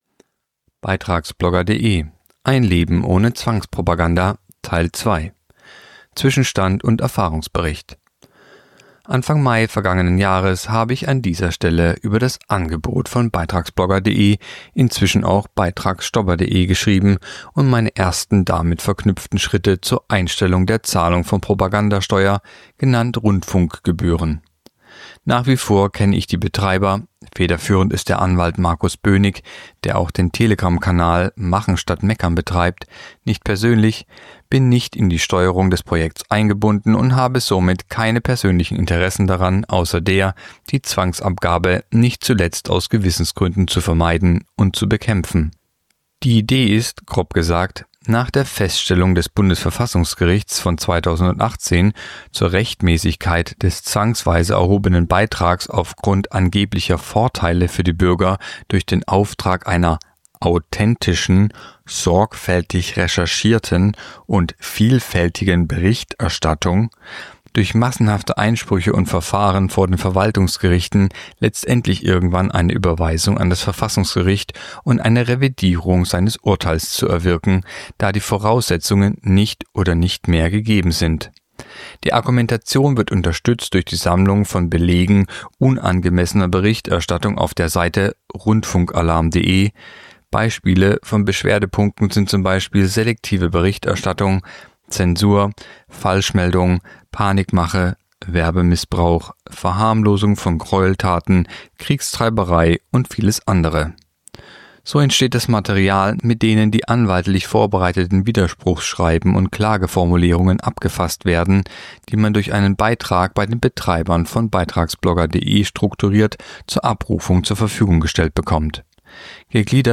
Sprecher